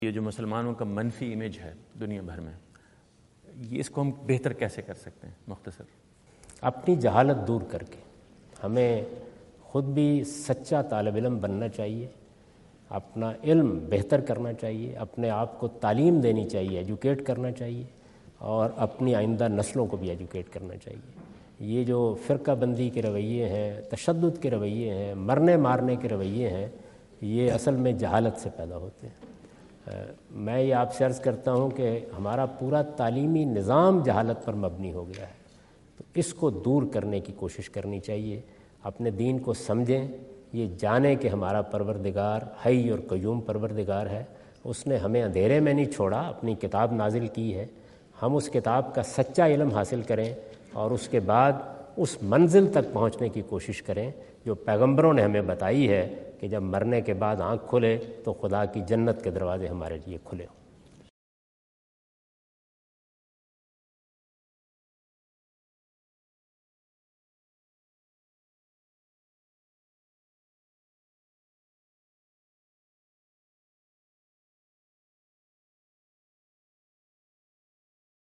Javed Ahmad Ghamidi answer the question about "How to Improve Image of Muslims?" asked at North Brunswick High School, New Jersey on September 29,2017.
جاوید احمد غامدی اپنے دورہ امریکہ 2017 کے دوران نیوجرسی میں "مسلمانوں کے خلاف منفی سوچ کا خاتمہ" سے متعلق ایک سوال کا جواب دے رہے ہیں۔